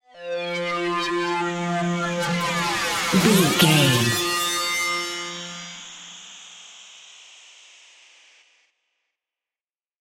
Atonal
scary
tension
ominous
dark
suspense
eerie
industrial
synthesiser
keyboards
ambience
pads
eletronic